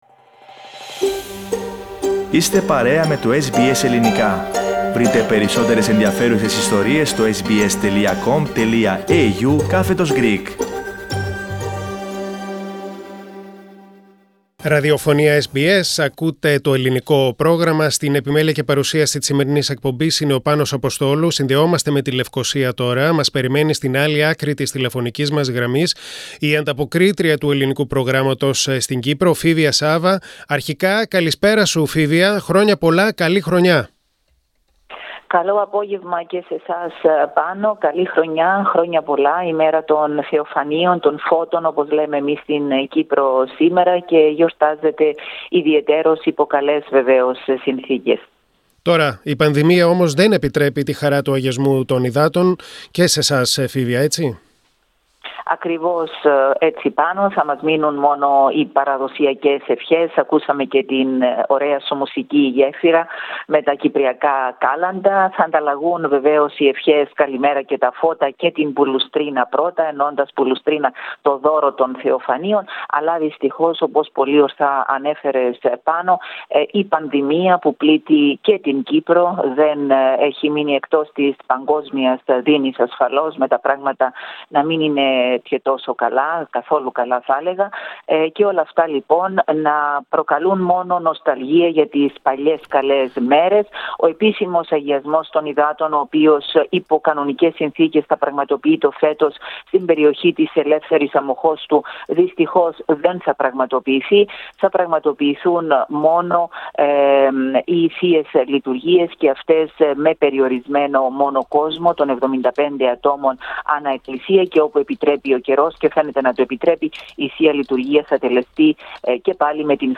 Οι Κύπριοι γιόρτασαν τα Θεοφάνεια μέσα στα σπίτια τους και χωρίς Αγιασμό των Υδάτων. Ακούστε την εβδομαδιαία ανταπόκριση από την Λευκωσία.